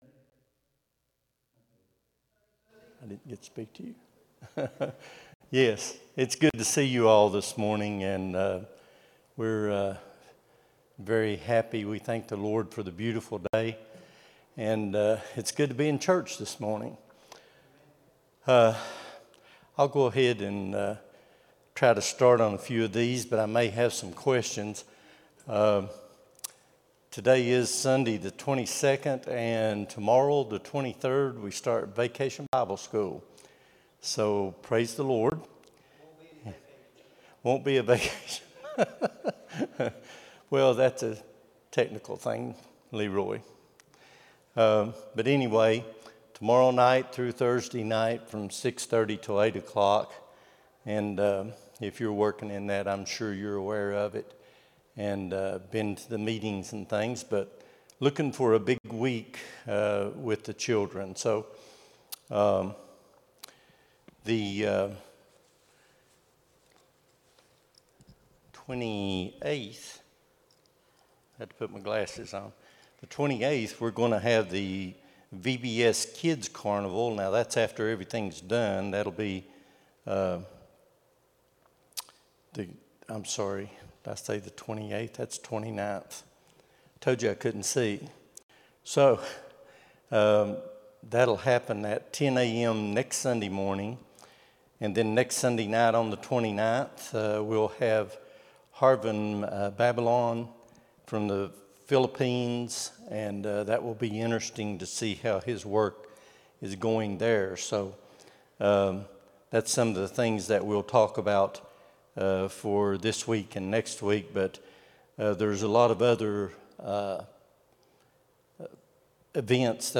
06-22-25 Sunday School | Buffalo Ridge Baptist Church